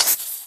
creeper1.ogg